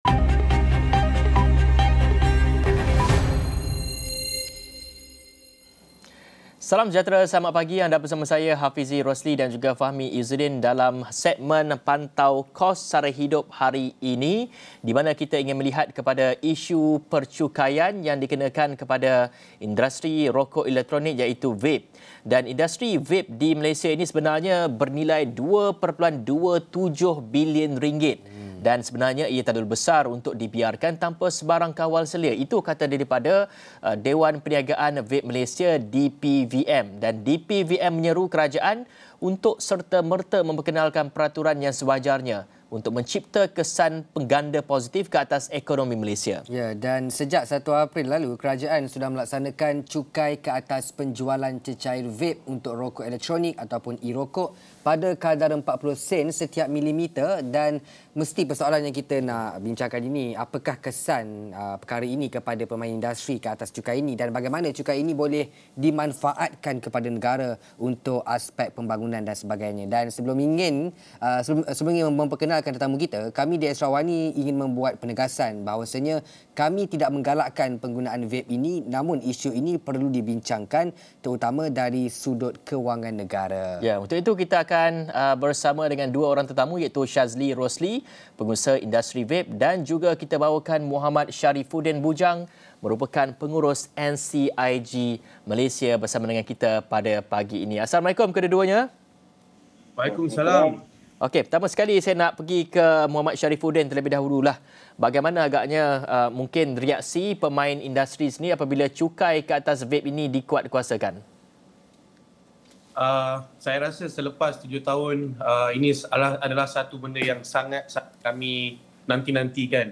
Ikuti diskusi dua pengusaha industri